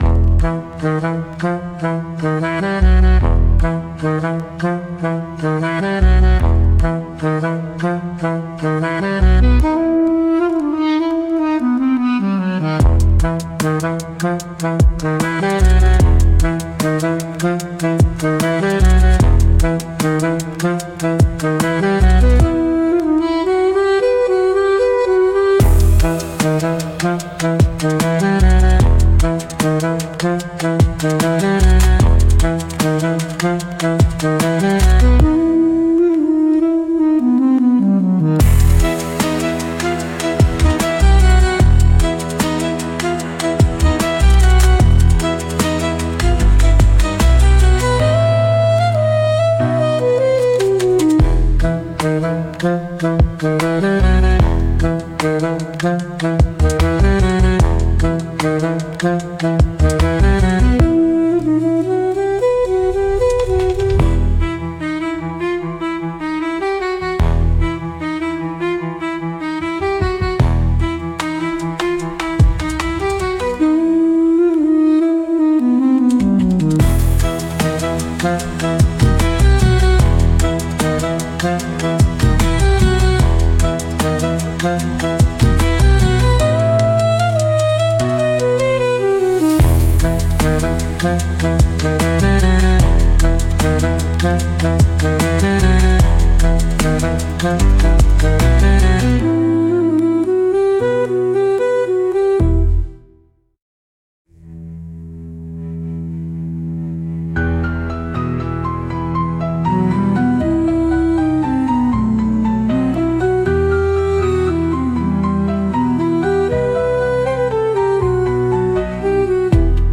soulfulduduktrip_1.mp3